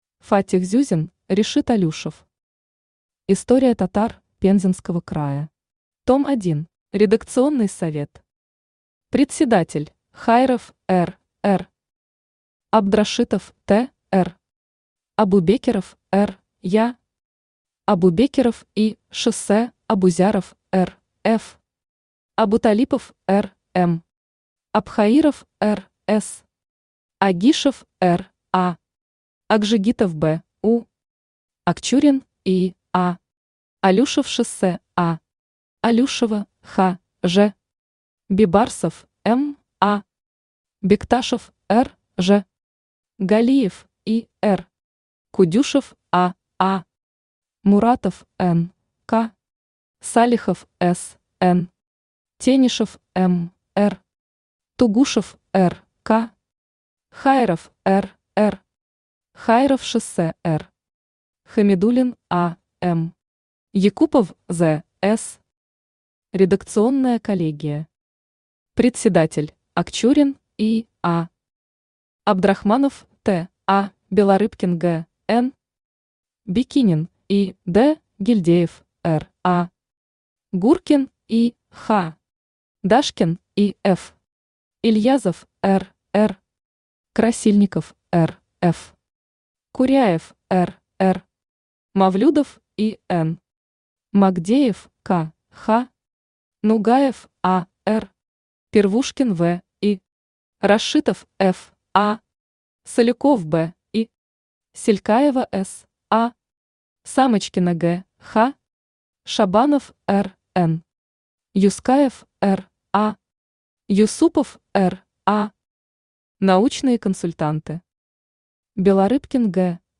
Аудиокнига История татар Пензенского края. Том 1 | Библиотека аудиокниг
Том 1 Автор Фаттих Мухомятович Зюзин Читает аудиокнигу Авточтец ЛитРес.